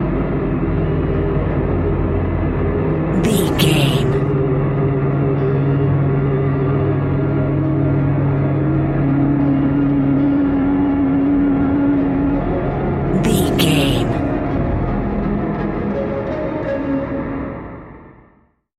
Fast paced
In-crescendo
Ionian/Major
C♯
dark ambient
EBM
synths
Krautrock